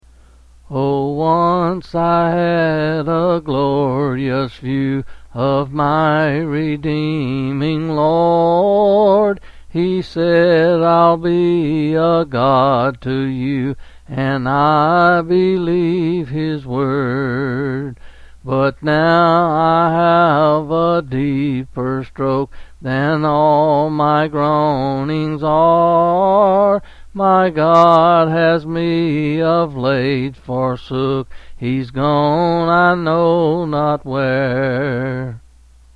Quill Pin Selected Hymn
C. M. 8 Lines